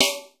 YEAH SNR HI.wav